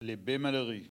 Enquête Arexcpo en Vendée-Lucus
Catégorie Locution